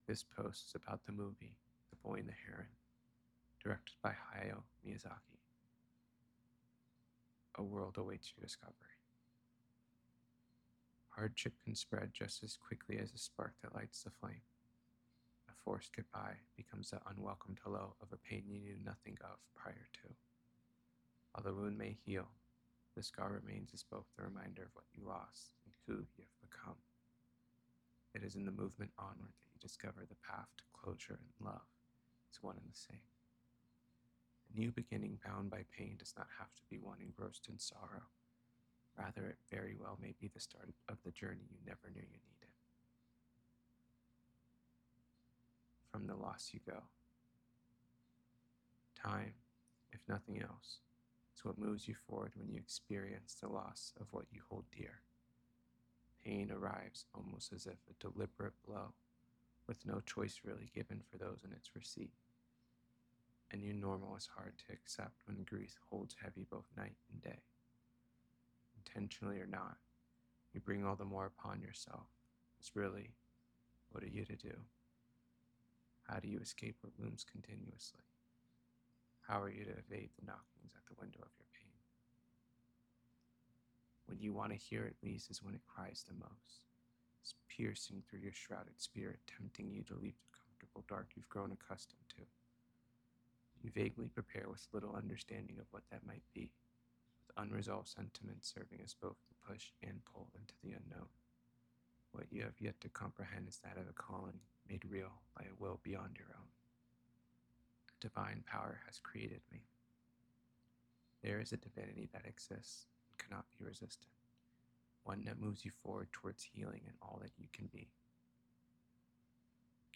the-boy-and-the-heron-to-know-a-story-reading.mp3